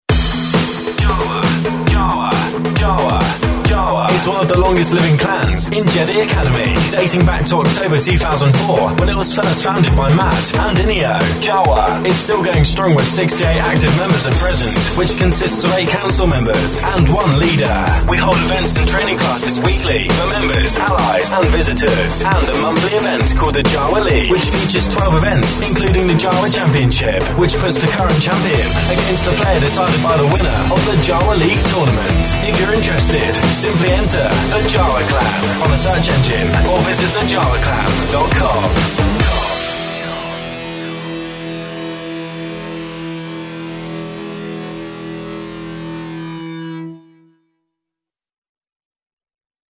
Example of a clan ad produced by JediKnightFM